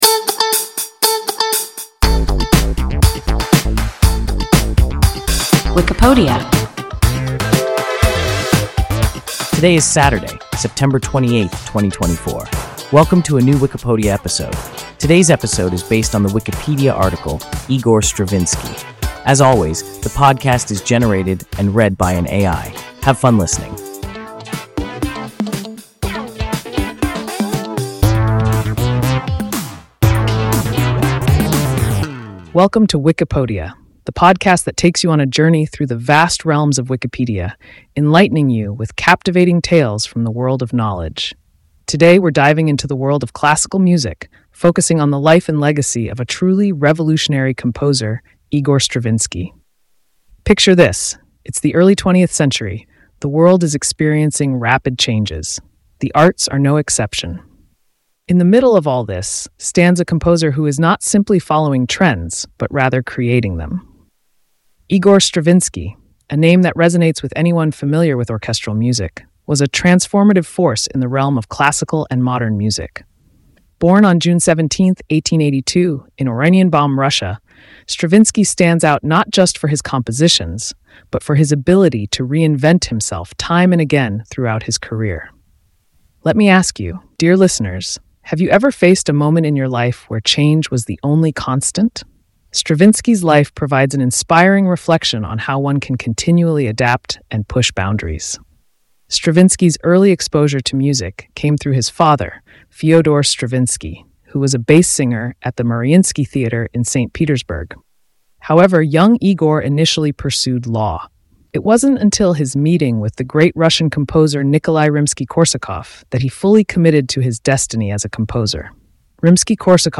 Igor Stravinsky – WIKIPODIA – ein KI Podcast